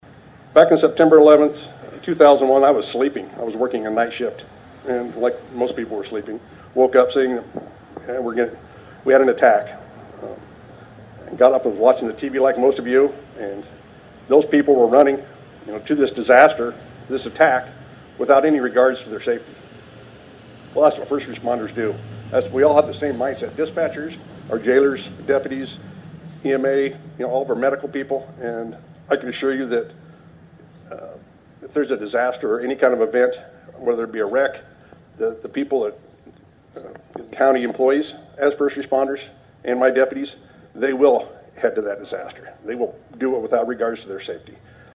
(Atlantic) The American Legion Post #43 held a Remembrance Day ceremony Thursday morning at the Atlantic City Park to honor first responders for their heroic efforts during the current day and the September 11, 2001, attacks on the United States.
Cass County Sheriff John Westering is the 30th Sheriff to serve Cass County since county government was formed in 1853.